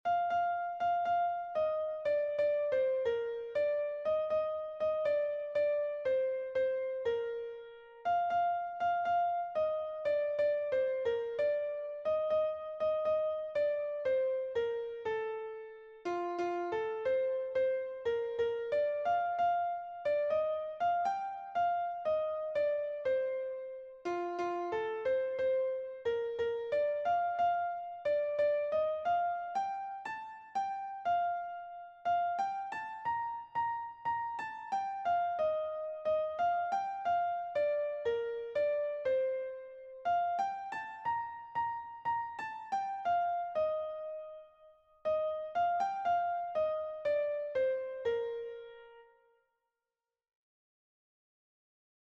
Hamarreko handia (hg) / Bost puntuko handia (ip)